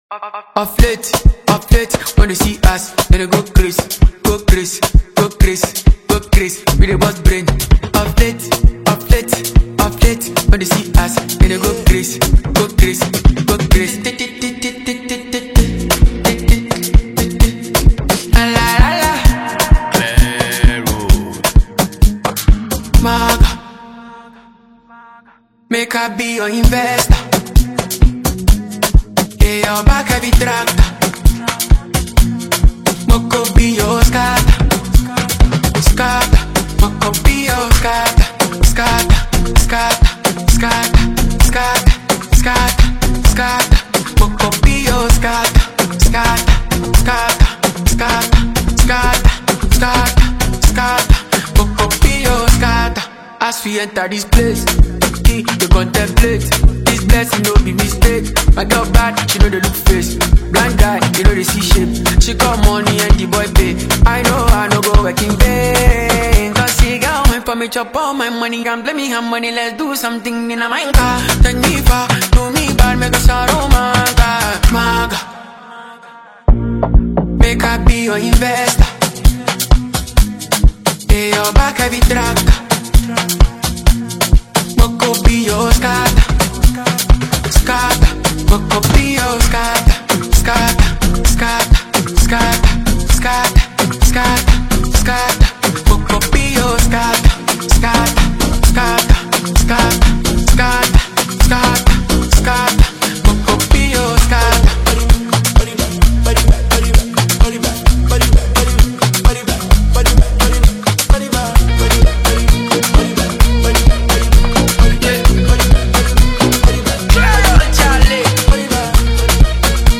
Ghana Music
High-Energy, Afro-Pop Banger
Built on a vibrant, percussive Afrobeats foundation